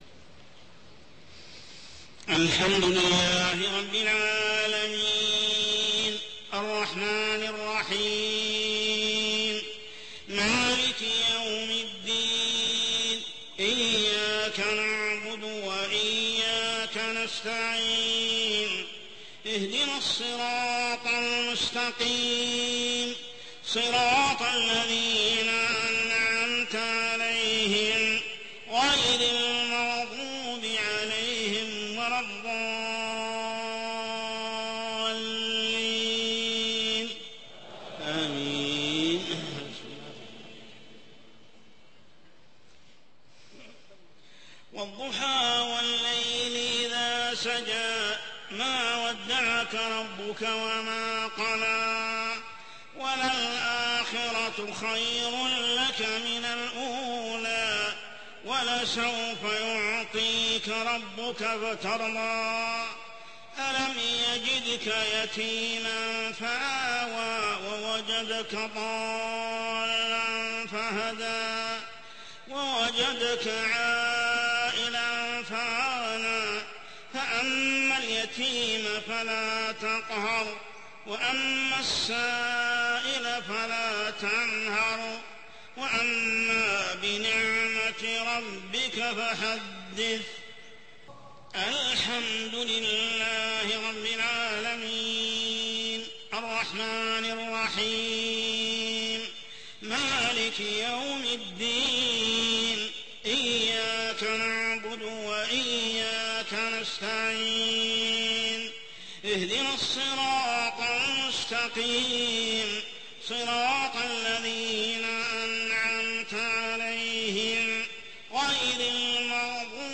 صلاة العشاء عام 1428هـ سورتي الضحى و الشرح كاملة | Isha prayer Surah Ad-duha and Ash-Sharh > 1428 🕋 > الفروض - تلاوات الحرمين